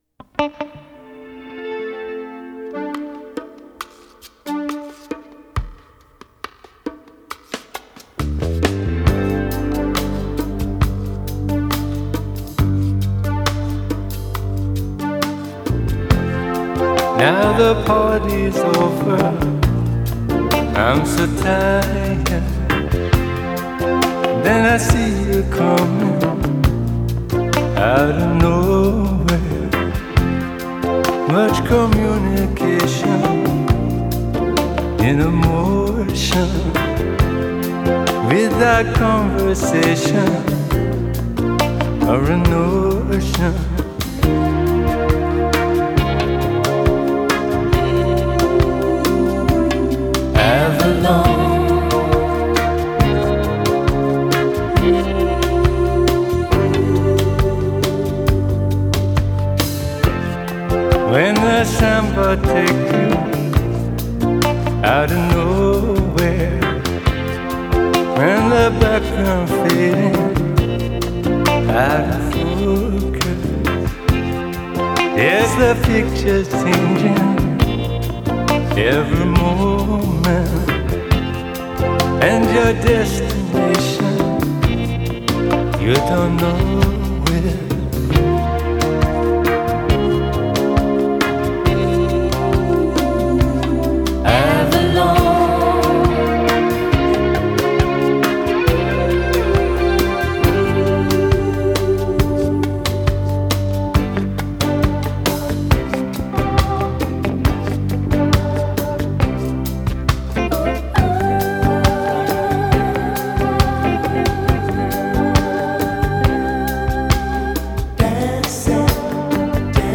Genre : Rock.